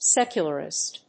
音節séc・u・lar・ist 発音記号・読み方
/‐rɪst(米国英語), ˈsekjʌlɜ:ɪst(英国英語)/